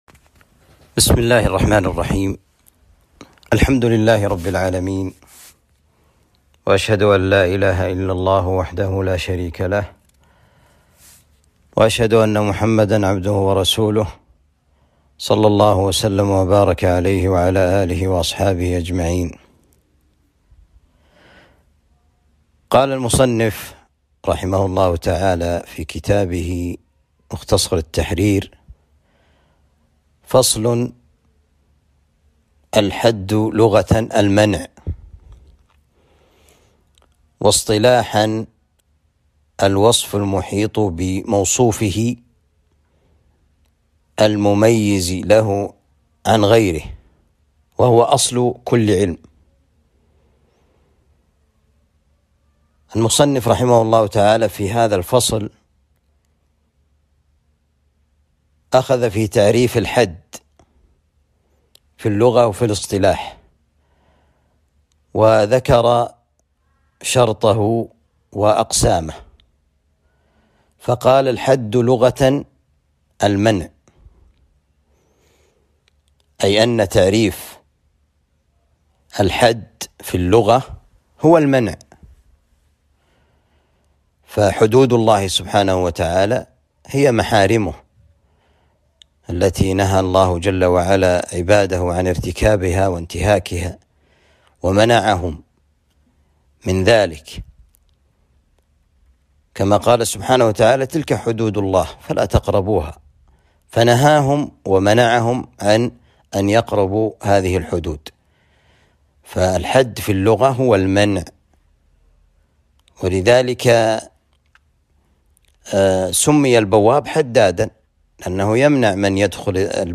التعليق على كتاب مختصر التحرير في أصول الفقه الدروس التعليق على كتاب مختصر التحرير في أصول الفقه المقطع 9.